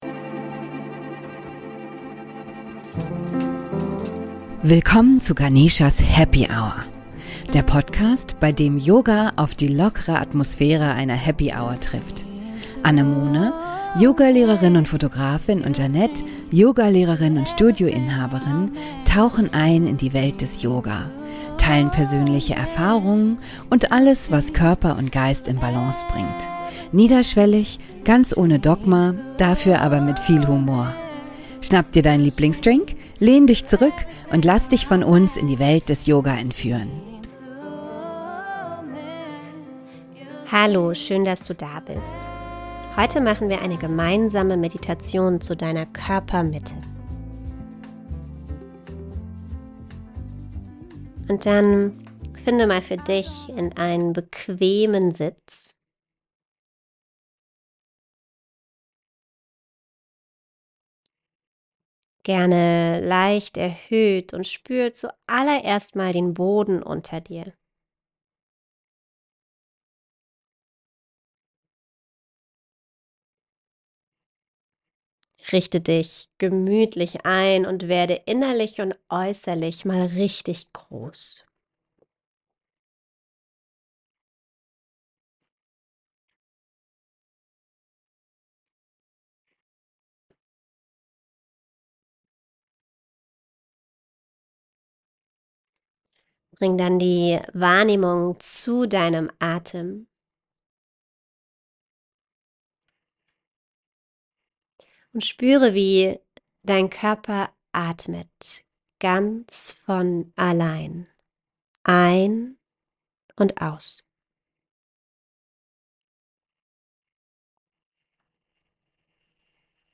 In dieser geführten Meditation laden wir dich ein, deine